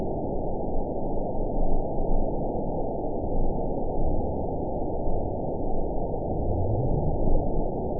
event 915262 date 11/25/22 time 22:13:38 GMT (3 years ago) score 8.95 location TSS-AB09 detected by nrw target species NRW annotations +NRW Spectrogram: Frequency (kHz) vs. Time (s) audio not available .wav